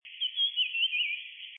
1-4溪頭頭烏線.mp3
烏線雀鶥 Alcippe brunnea brunnea
南投縣 鹿谷鄉 溪頭
錄音環境 森林
雄鳥歌聲
Sennheiser 型號 ME 67